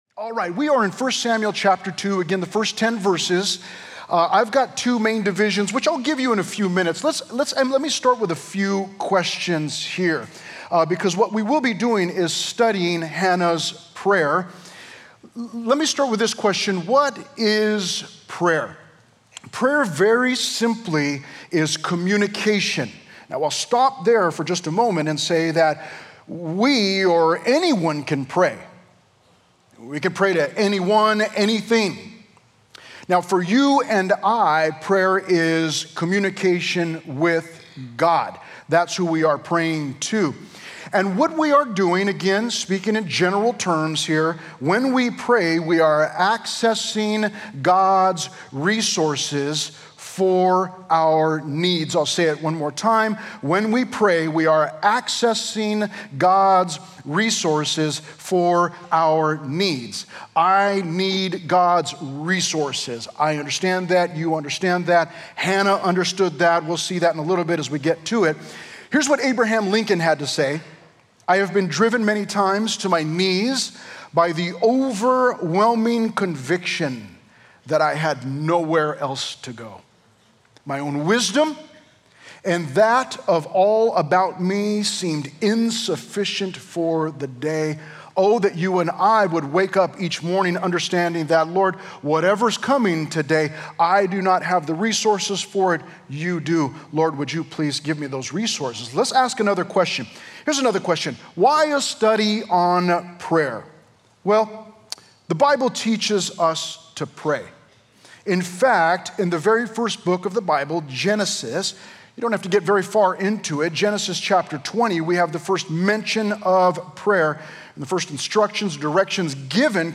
A verse-by-verse sermon through 1 Samuel 2:1-10